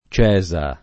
— per C. nel Veneto e in val di Chiana, pn. loc. con -e- chiusa